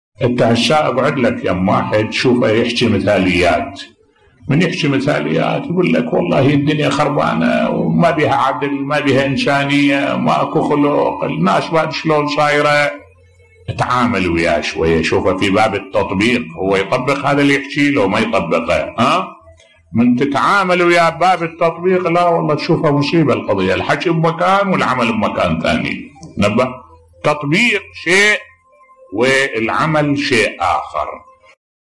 ملف صوتی المتناقضين بصوت الشيخ الدكتور أحمد الوائلي